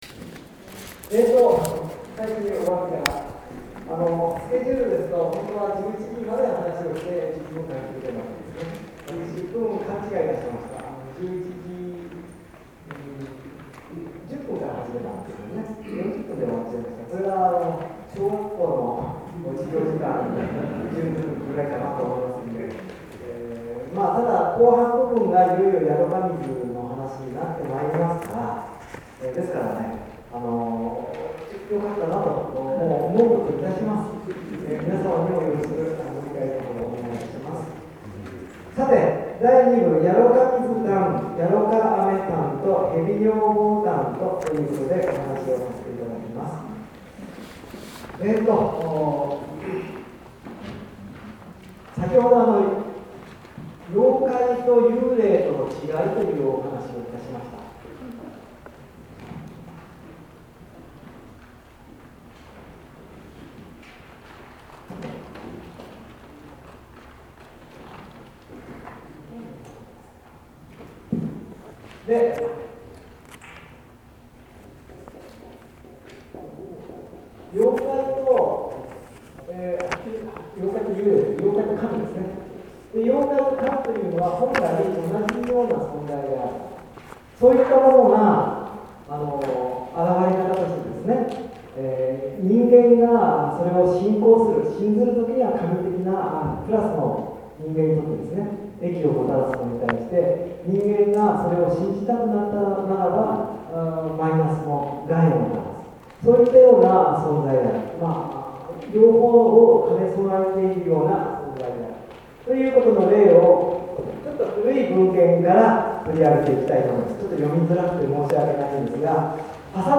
午後は三つの物語の朗読を中心とした上演である。
三つの犬山の伝承を語り部の朗読で聴いた。